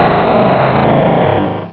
Cri de Groudon dans Pokémon Diamant et Perle.